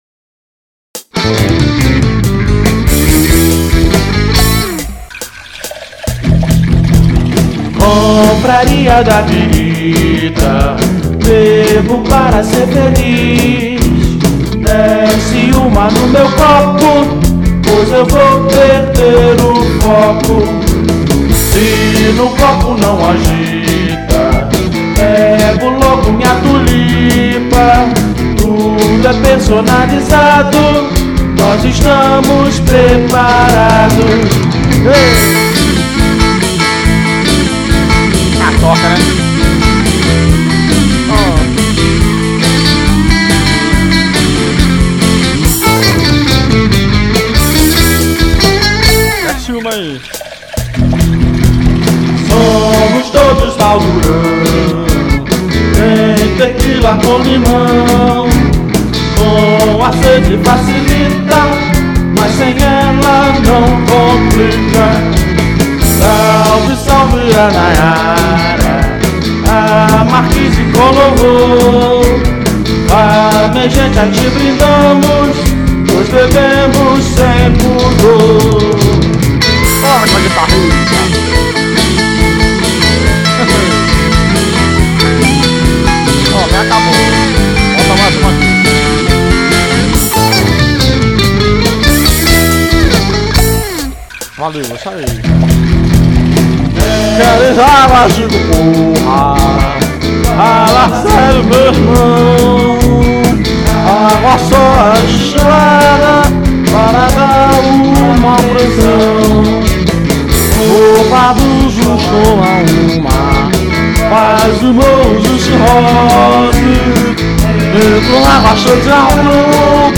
A música abaixo é a versão rock do hino.
bateria, guitarra, baixo, teclado e cantando